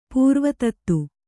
♪ pūrva tattu